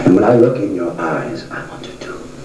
THE VOICE OF LANCE HENRIKSEN